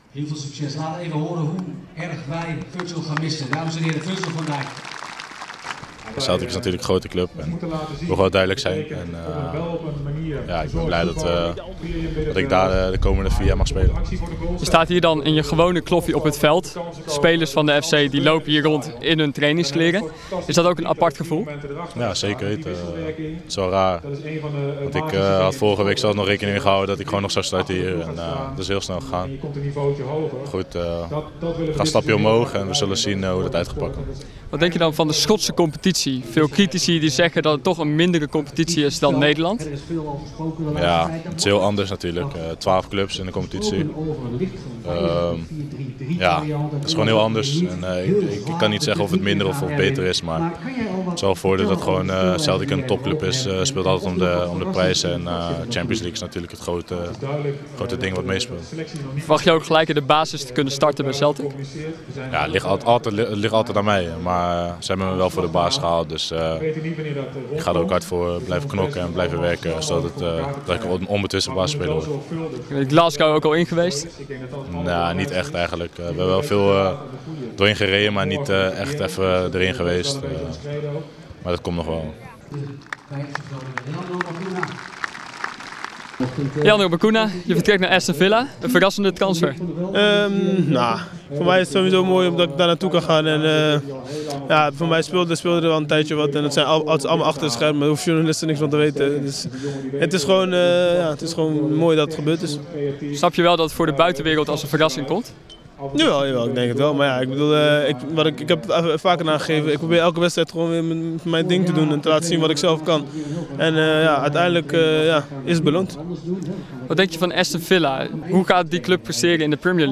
De afscheidnemende Virgil van Dijk en Leandro Bacuna in gesprek